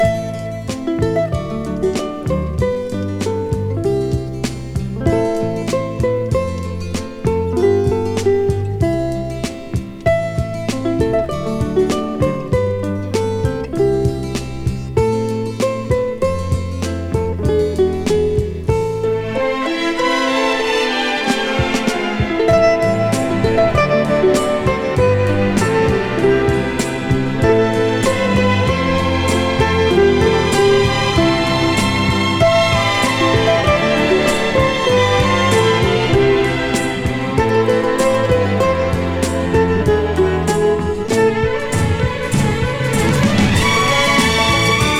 イージーリスニング・スタンダードとも言える楽曲がウクレレとストリングスで彩り豊かに。
Jazz, Pop, Easy Listening　USA　12inchレコード　33rpm　Stereo